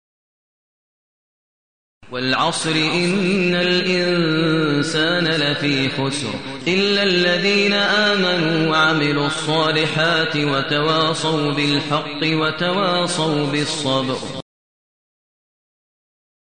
المكان: المسجد النبوي الشيخ: فضيلة الشيخ ماهر المعيقلي فضيلة الشيخ ماهر المعيقلي العصر